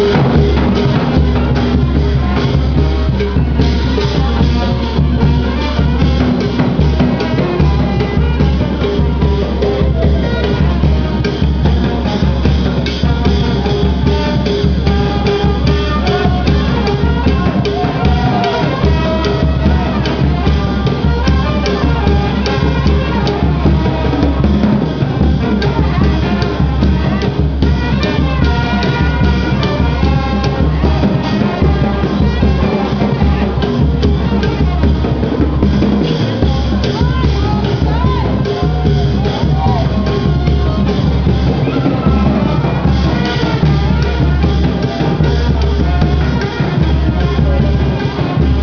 Musikgruppen spielten zur Feier des Tages auf.
Die Musik schien mir nicht typisch Thai zu sein.